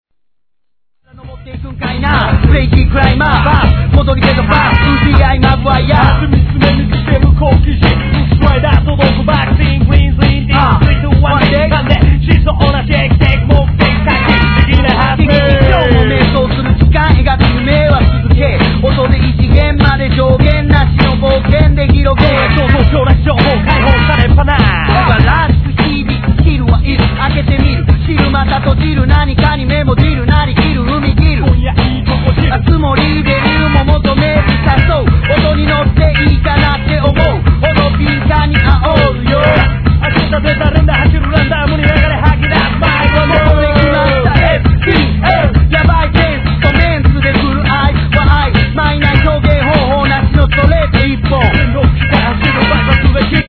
1. JAPANESE HIP HOP/R&B